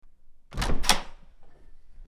Door Opening 02
Door_opening_02.mp3